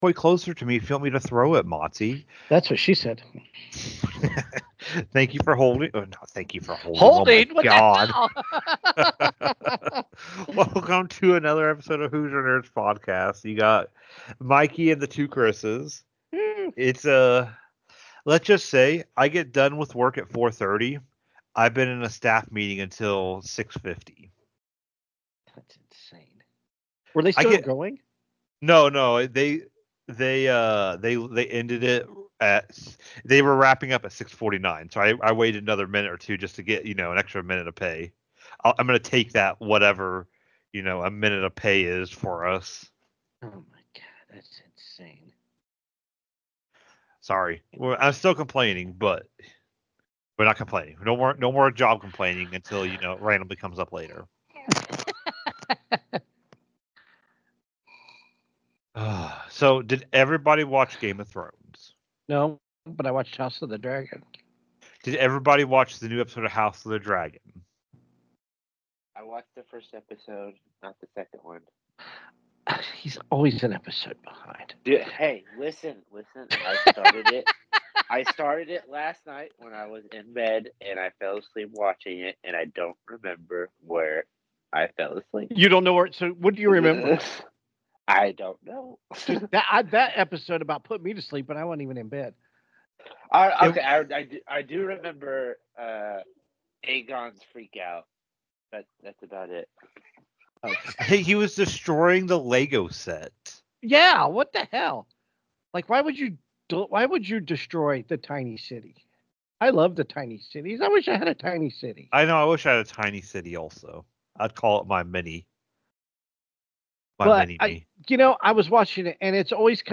Four nerds get together to talk about our thoughts on everything involving TV, movies, games, tech, and sports. These nerdy conversations will surely make you a NERD.